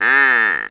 ANIMAL_Duck_09_mono.wav